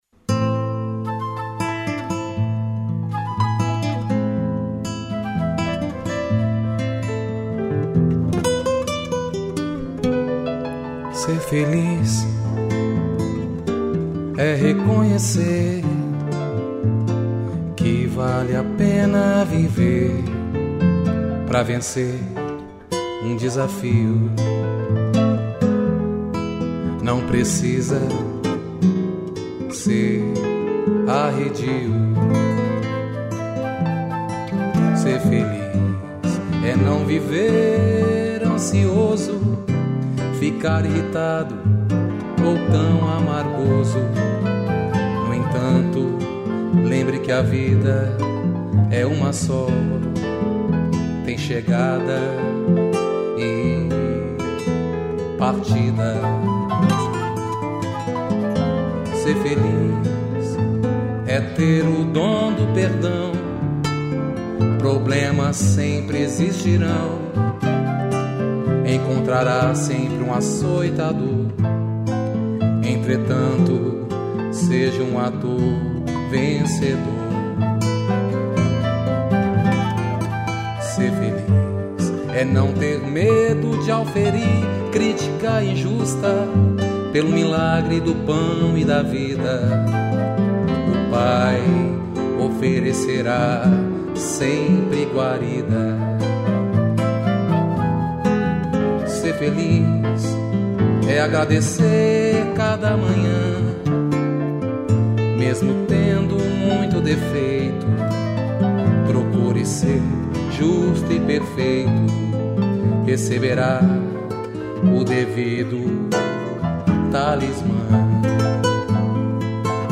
piano e flauta